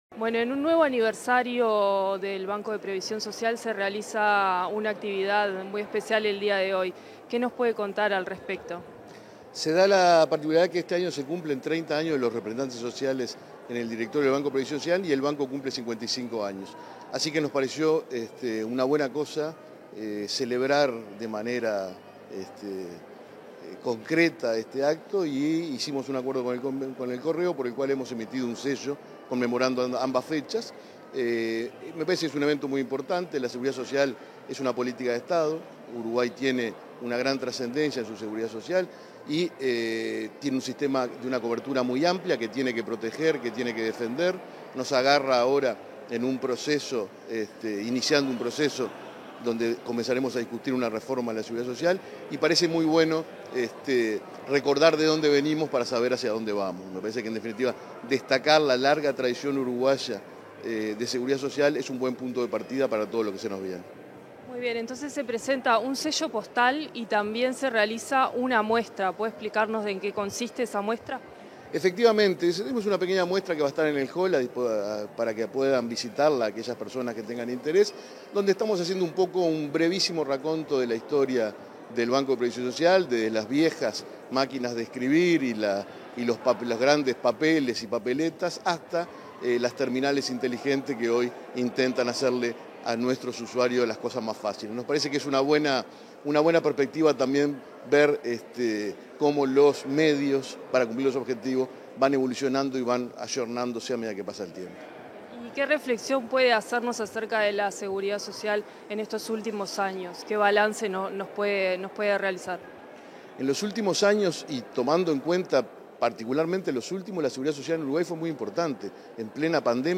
Entrevista al presidente del BPS, Alfredo Cabrera